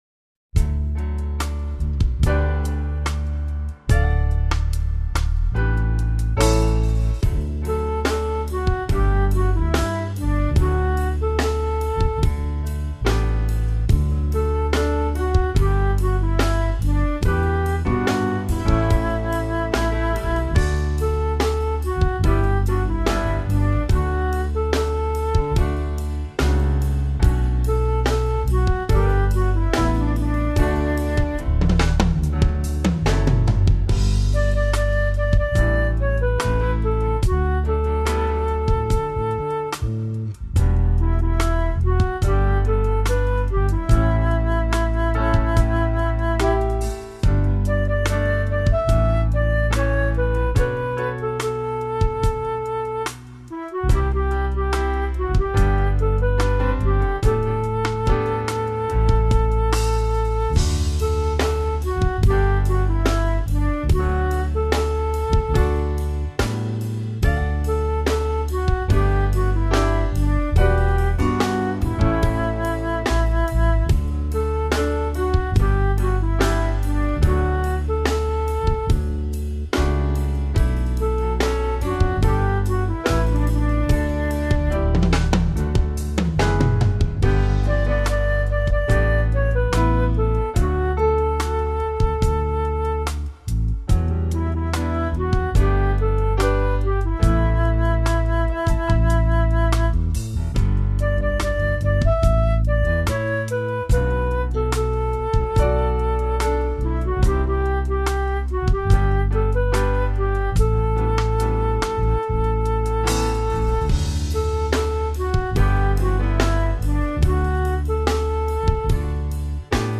It sounds like a song for personal devotional use rather than liturgy.